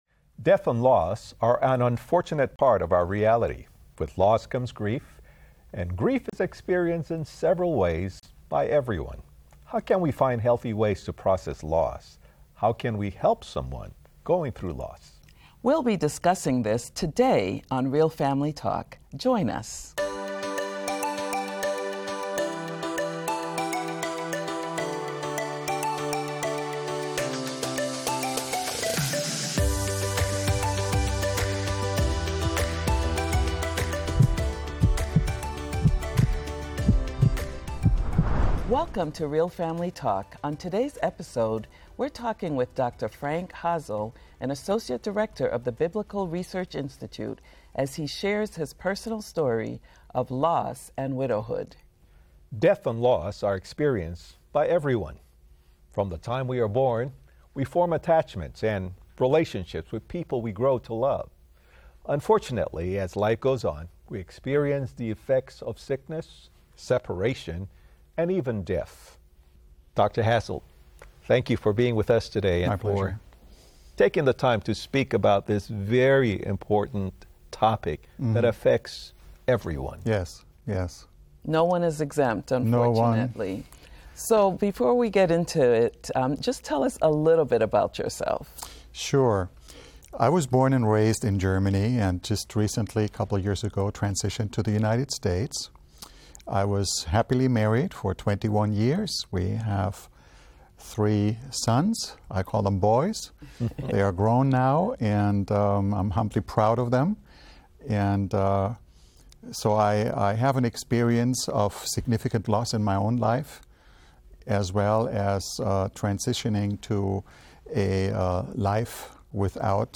Join Real Family Talk for a heartfelt discussion on grief and loss.